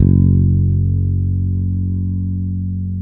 -MM BRYF F#2.wav